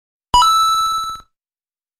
Tesla Lock Sounds & Chimes Collection: Movies, Games & More - TeslaMagz
Mario Coin Sound.wav